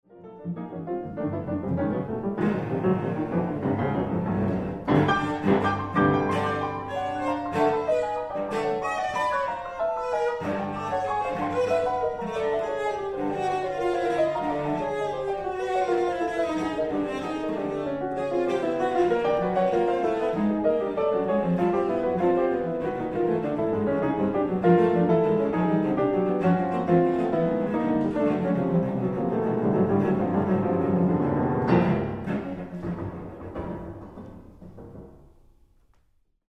violoncello & piano